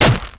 attacktear.wav